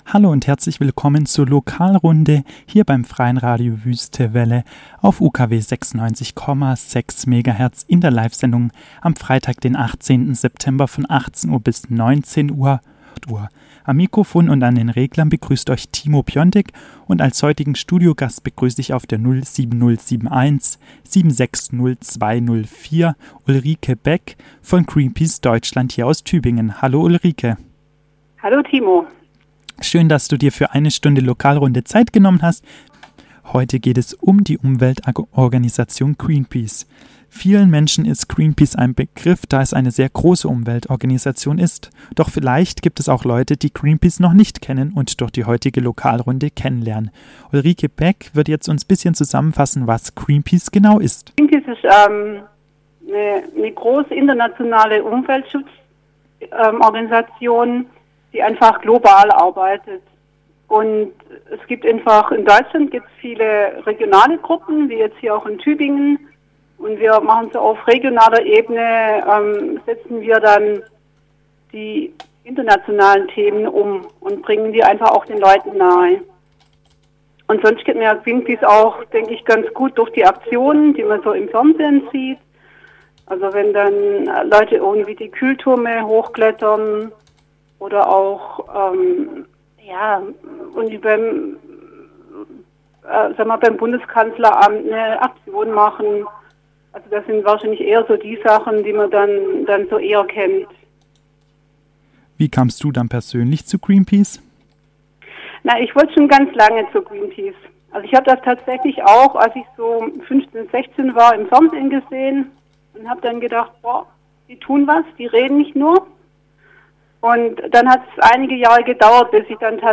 interviewte am Telefon